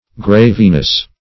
Graveness \Grave"ness\, n.